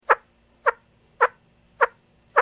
琴ヶ浜の汀線（波打ち際）近くの砂は、非常に良く鳴る。
鳴り砂の音が聴けます。